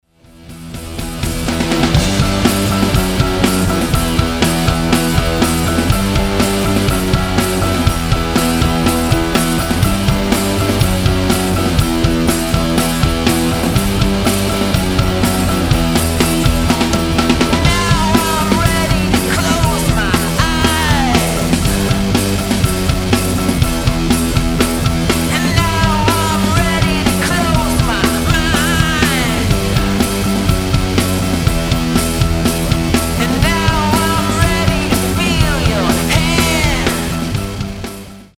• Качество: 320, Stereo
мужской голос
громкие
жесткие
garage rock
60-е